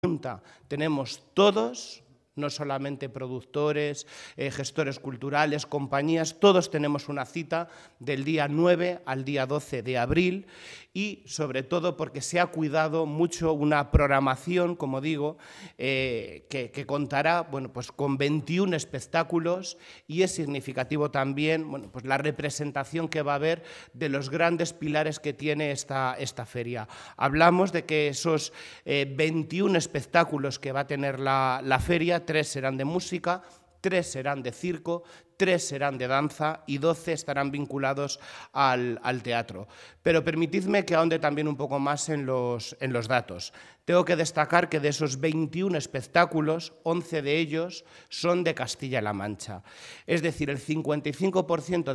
Lo ha avanzado el consejero de Educación, Cultura y Deportes, Amador Pastor, en la rueda de prensa de presentación de la muestra, celebrada en la delegación de la Junta de Albacete.